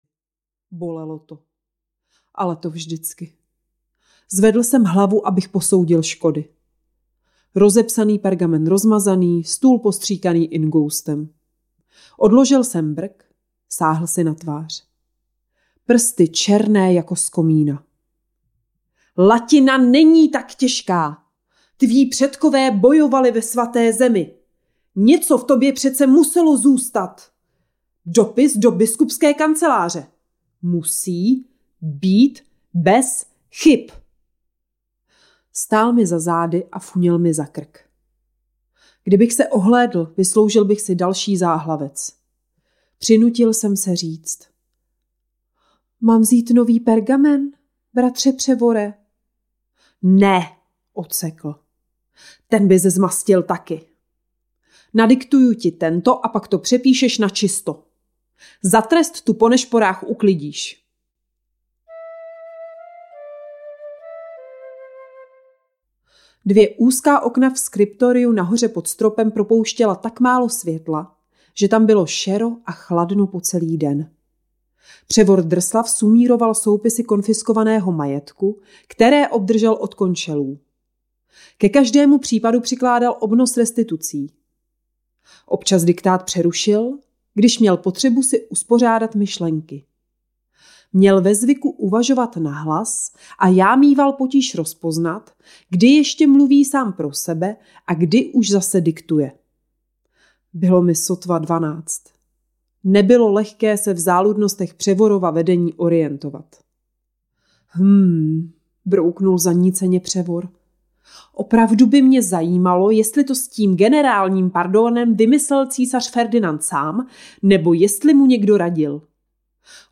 Za devatero mlhovinami audiokniha
Ukázka z knihy